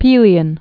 (pēlē-ən, -ôn), Mount